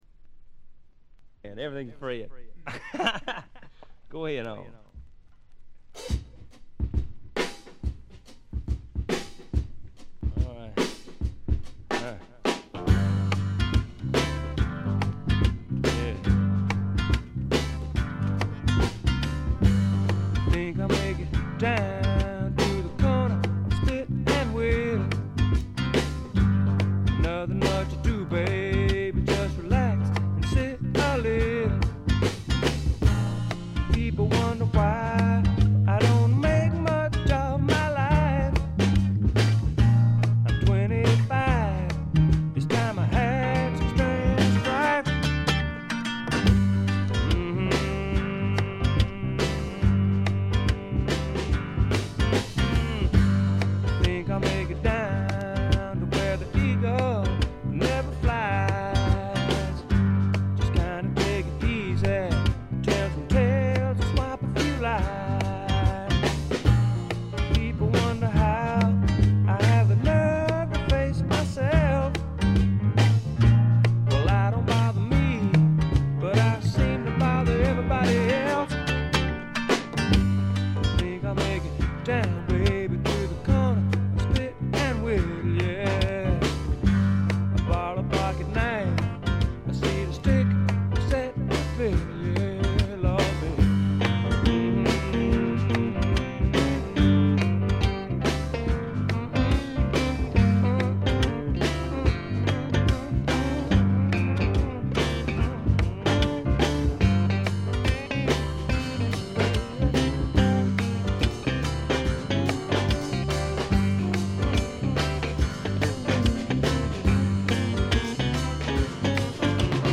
部分試聴ですが、軽微なチリプチ少々。
あまりナッシュビルぽくないというかカントリーぽさがないのが特徴でしょうか。
試聴曲は現品からの取り込み音源です。
Guitar, Vocals, Piano, Vibes